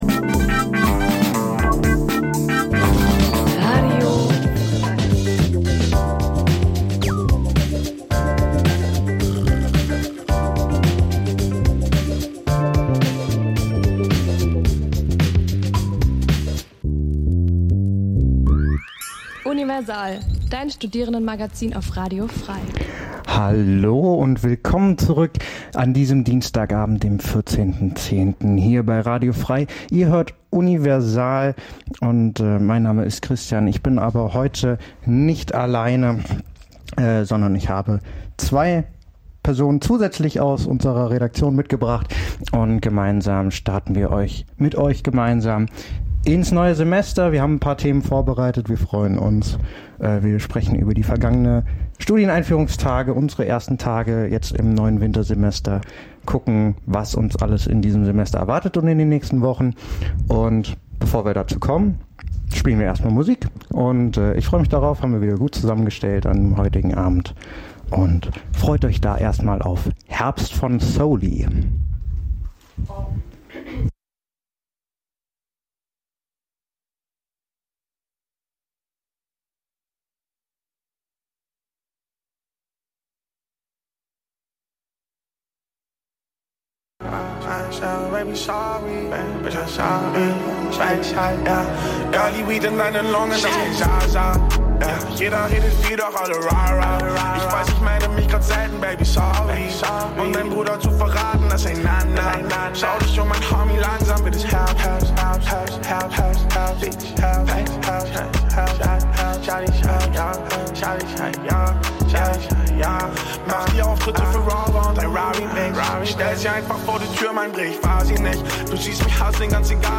Die Sendungen werden gemeinsam vorbereitet - die Beitr�ge werden live im Studio pr�sentiert.